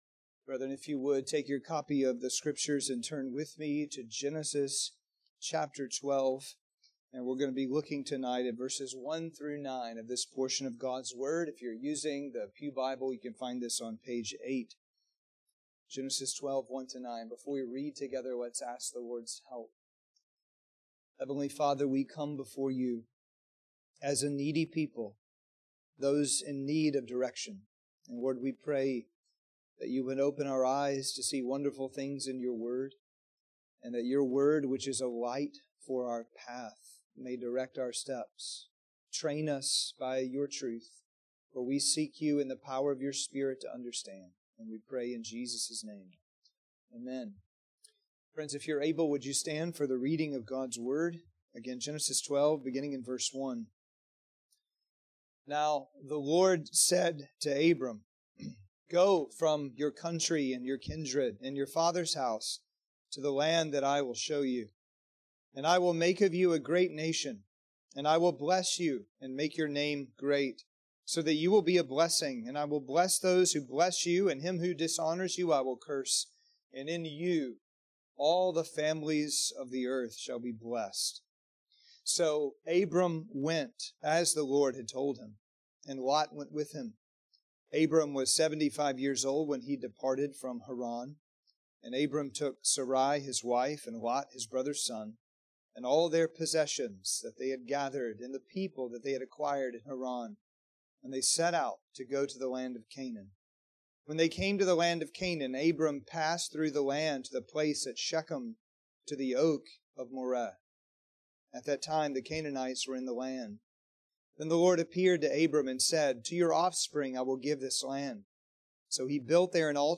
Sermons and Adult Sunday School from Grace Presbyterian Church, Douglasville, Georgia